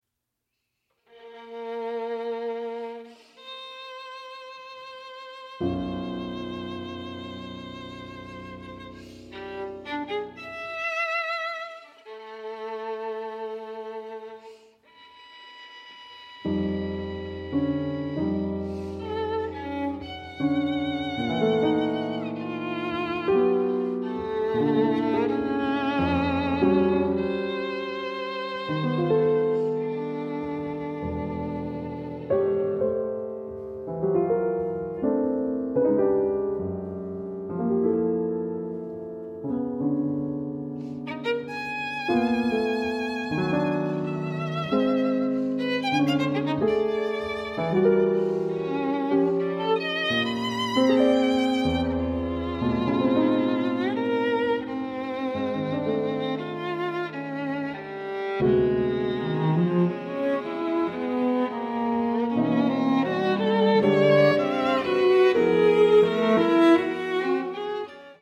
chamber works for various instrumentations
Andante